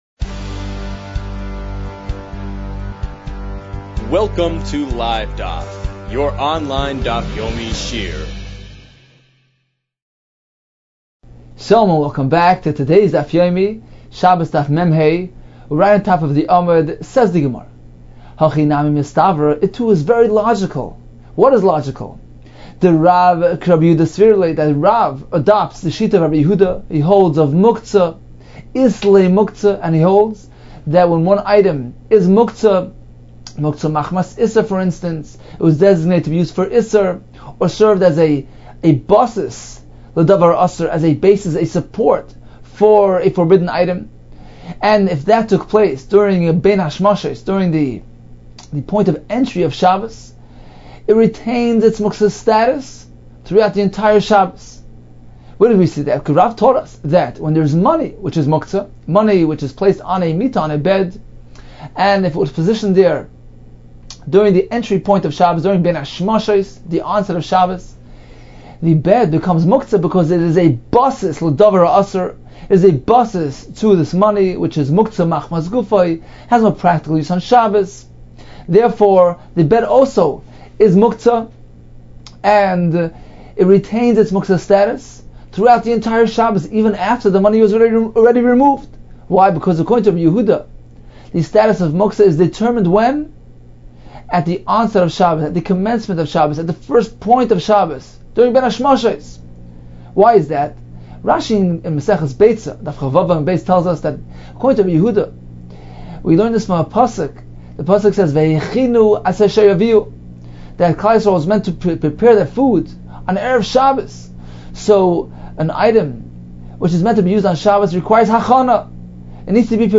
We are pleased to offer a daily Shiur on Daf Yomi in HD video format featuring on-screen charts and diagrams for summary and review throughout this Shiur on Shabbos 44